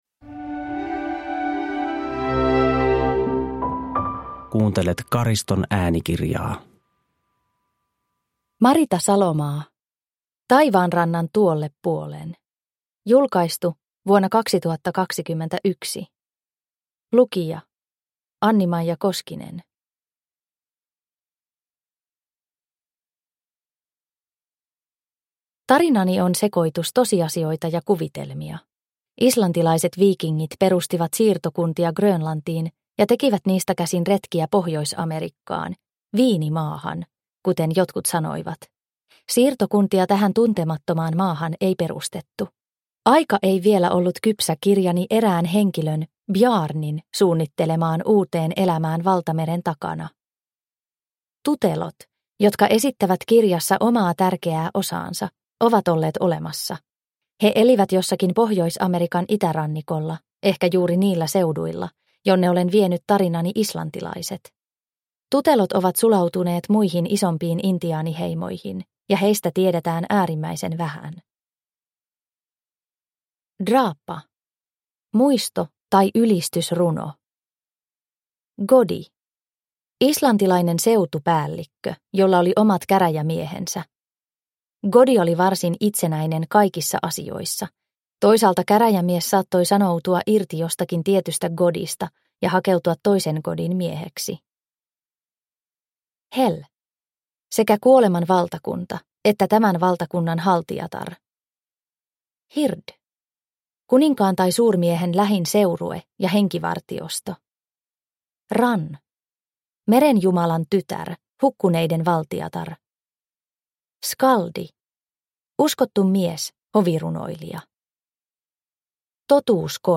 Taivaanrannan tuolle puolen – Ljudbok – Laddas ner